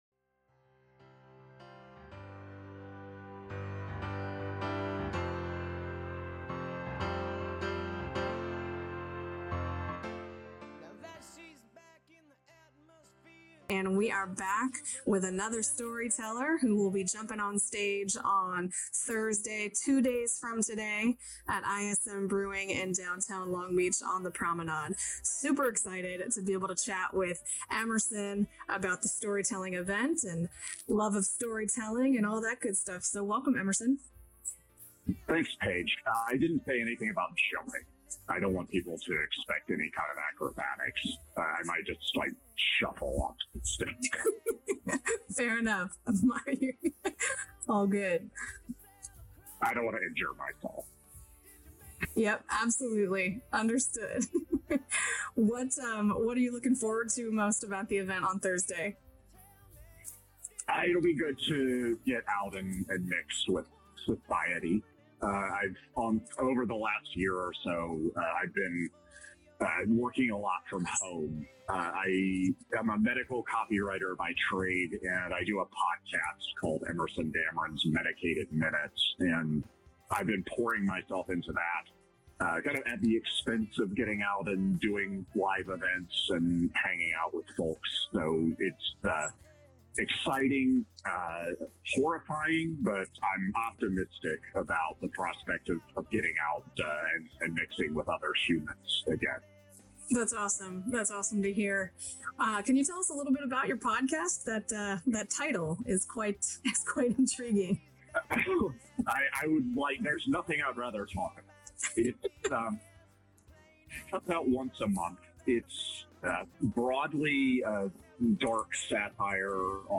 This interview aired live on CityHeART Radio on Tuesday Feb 29 at 12:30pm during Talk to Me Tuesdays!